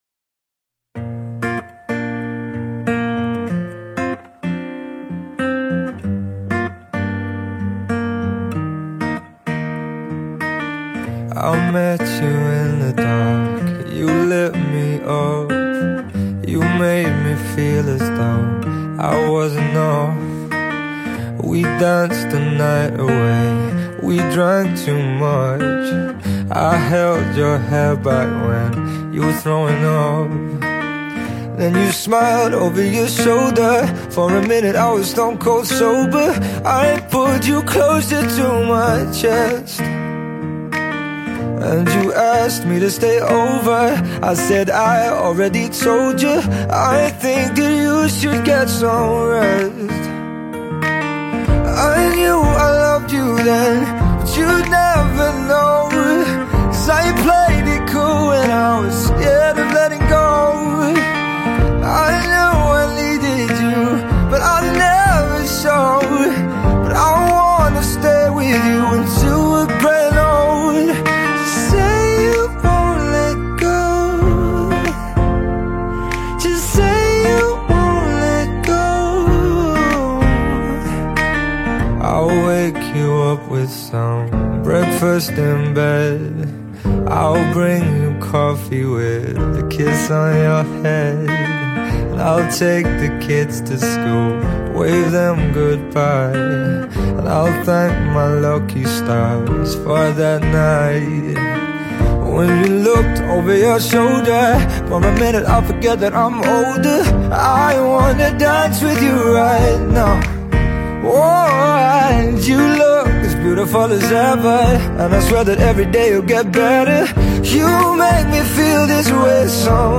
The Romantic acoustic ballad was Released on September 9